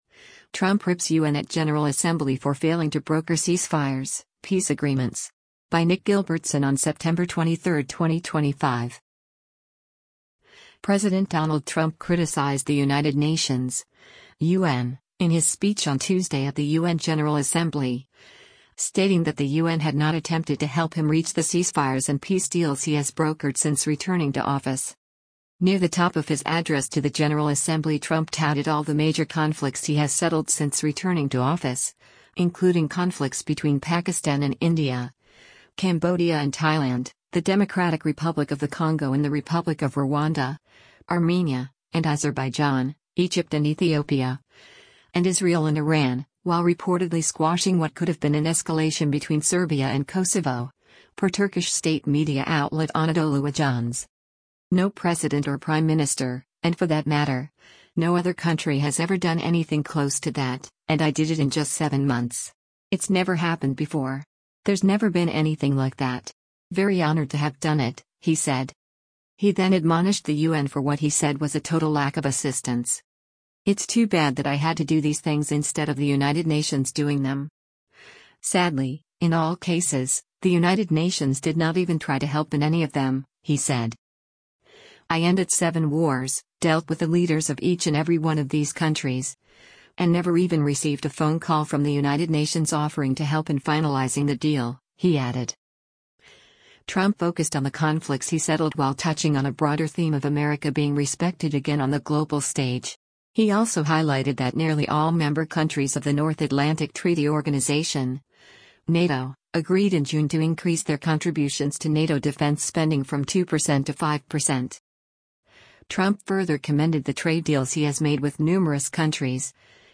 NEW YORK, NEW YORK - SEPTEMBER 23: U.S. President Donald Trump speaks during the 80th sess
President Donald Trump criticized the United Nations (U.N.) in his speech on Tuesday at the U.N. General Assembly, stating that the U.N. had not attempted to help him reach the ceasefires and peace deals he has brokered since returning to office.